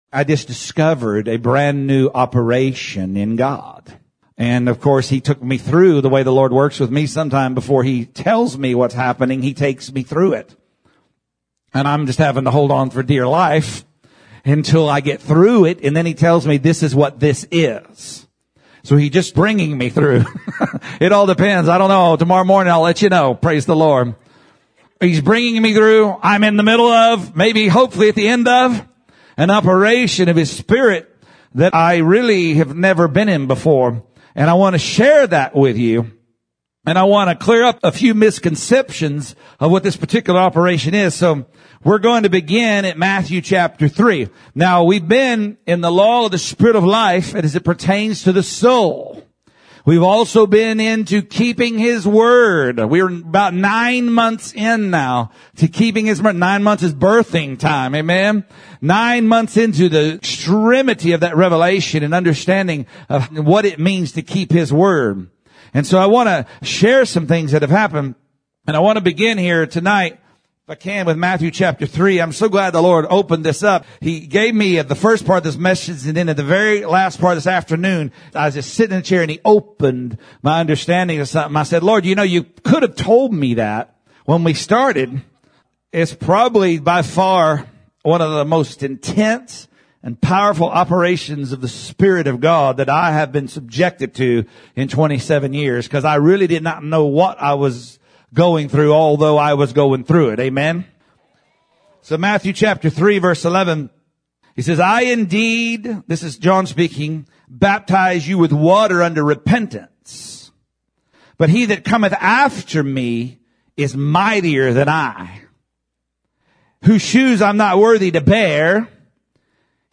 Sermon continuing the teaching about a new law of life for our souls and how we can go higher with God if we ask Him to baptize us with fire to draw things out